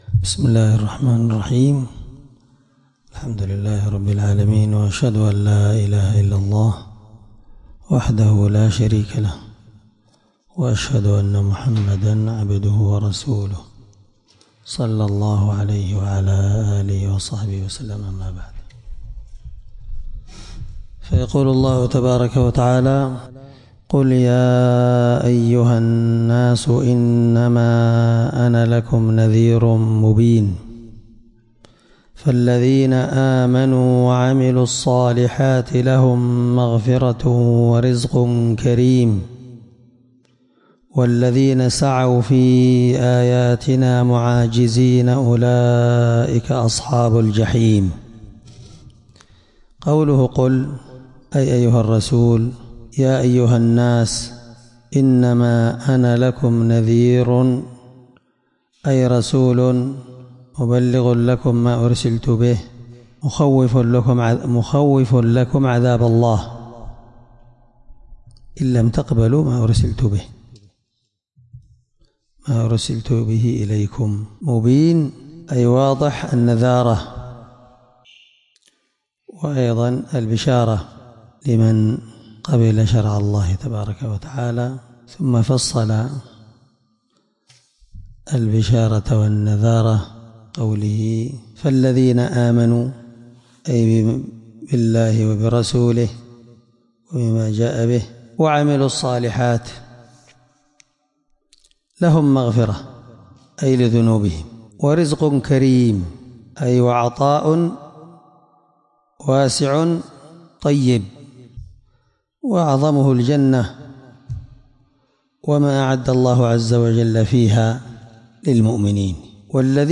الدرس20تفسير آية (49-51) من سورة الحج